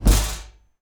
sword-hit.wav